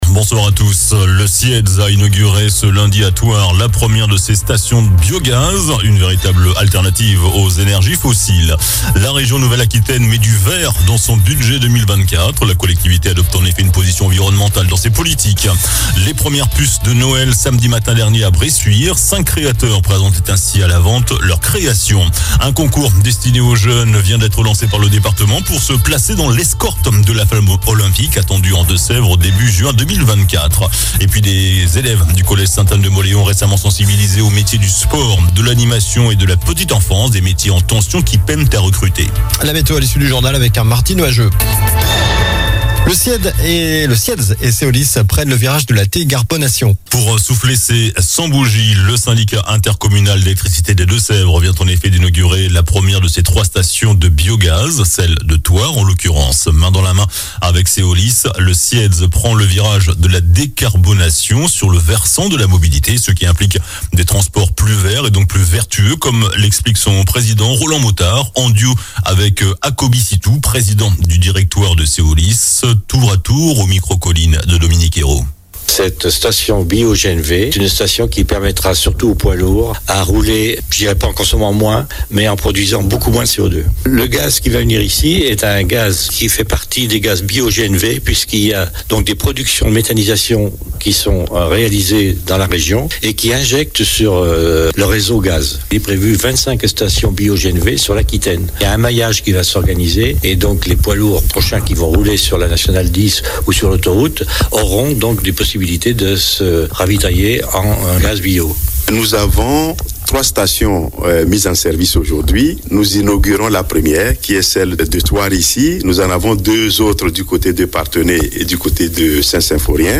Journal du lundi 18 décembre (soir)